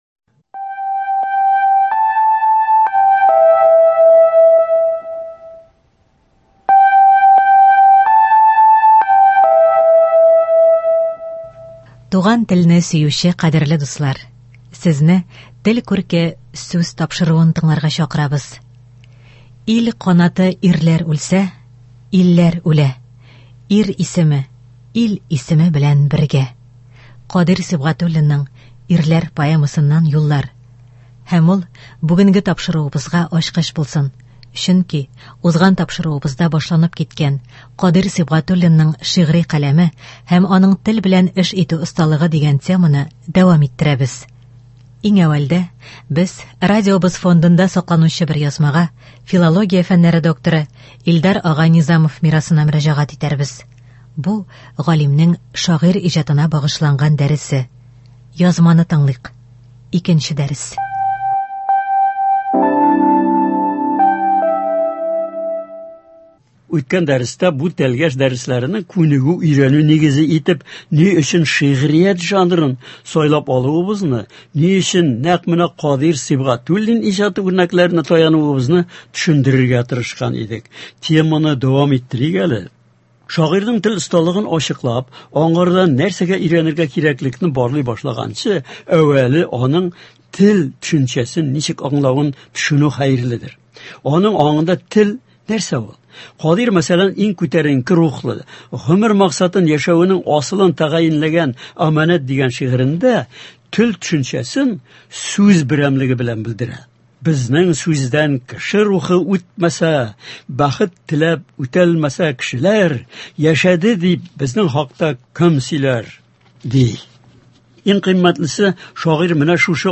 Һәр төбәкнең үз диалекты, үзенчәлекләре бар. Бу тапшыруда күренекле галимнәр, язучылар халкыбызны дөрес сөйләшү, дөрес язу серләренә өйрәтә.